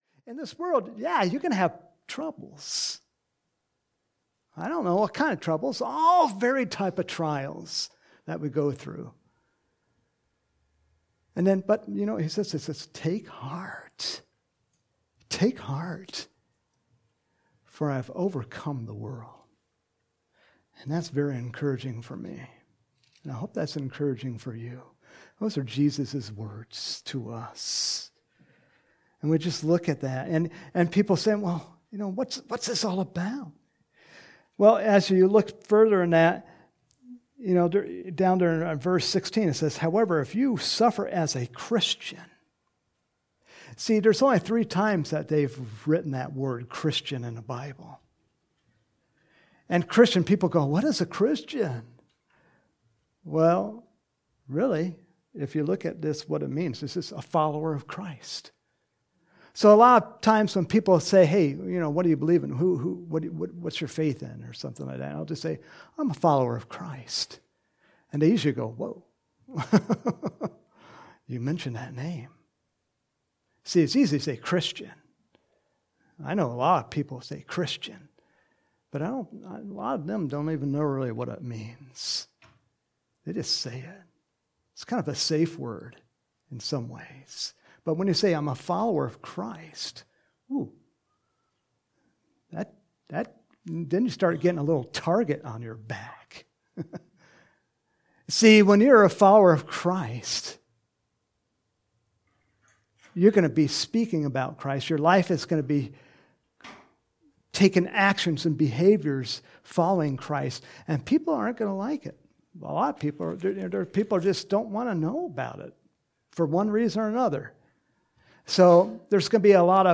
There is no video file for this sermon, only an audio file.
Sunday Morning Sermon